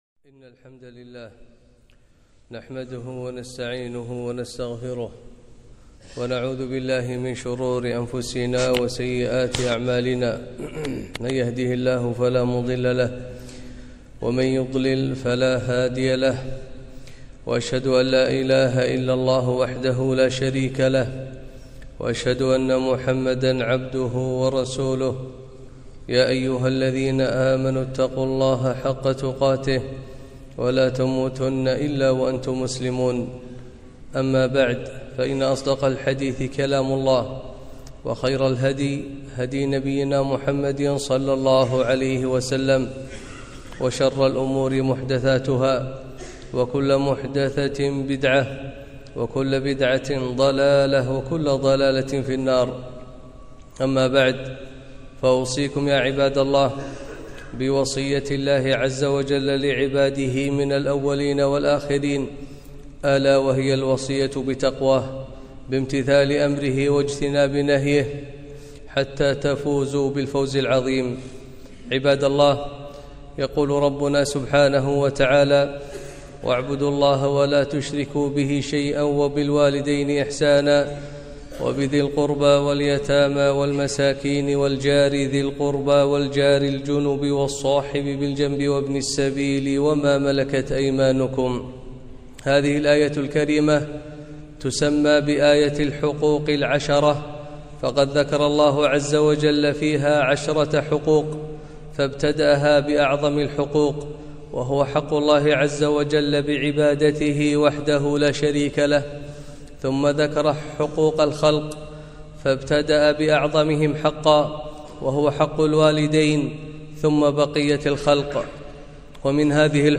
خطبة - حق الجار